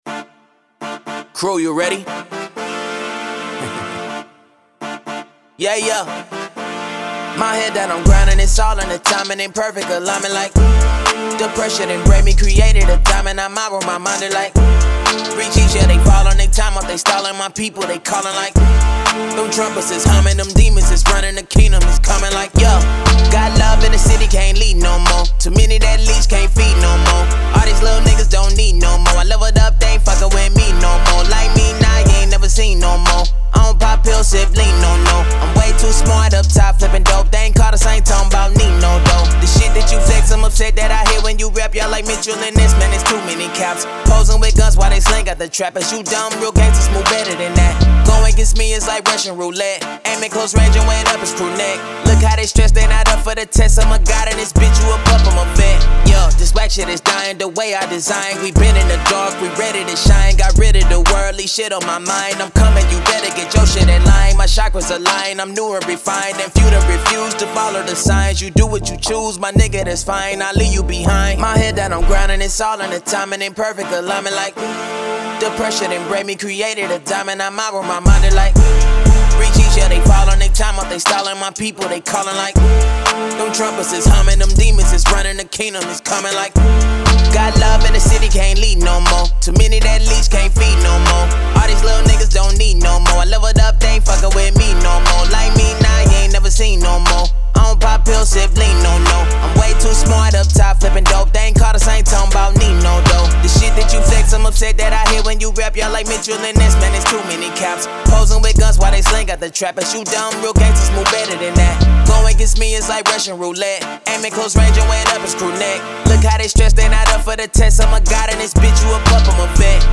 Hip Hop
D minor
Street, trap, high energy vibe